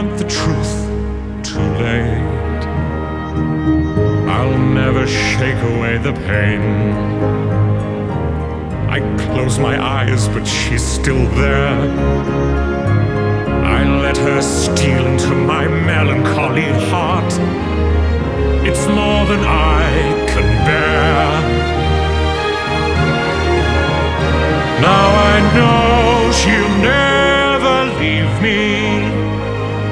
• Soundtrack
somber Broadway-influenced power ballad